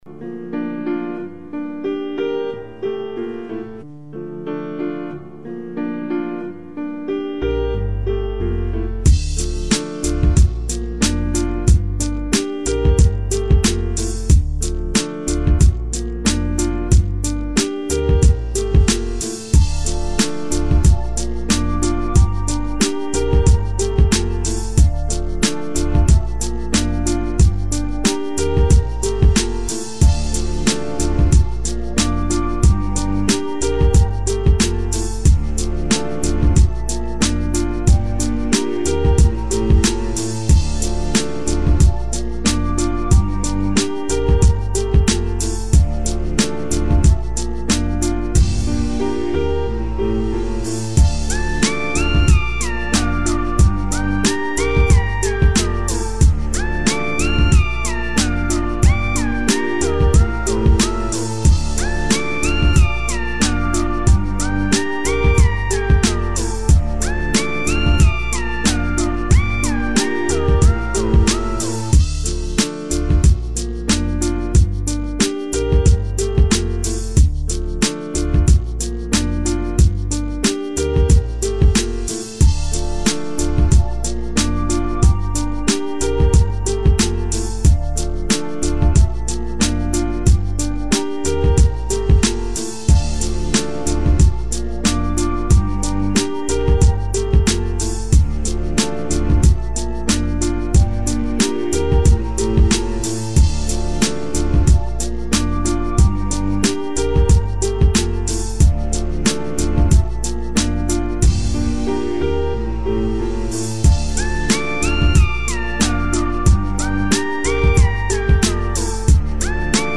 Категория: Instrumentals